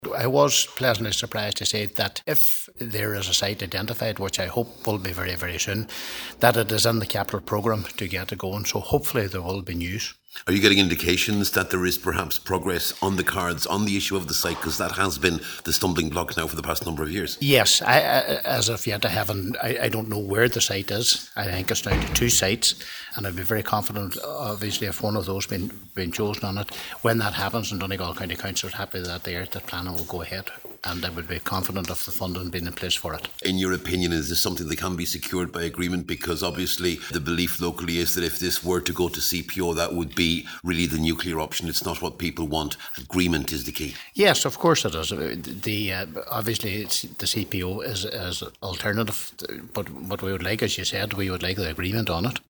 Cllr Crossan says the reply is a very positive one: